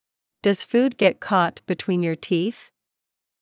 ﾀﾞｽﾞ ﾌｰﾄﾞ ｹﾞｯ ｶｩﾄ ﾋﾞﾄｩｳｨｰﾝ ﾕｱ ﾃｨｰｽ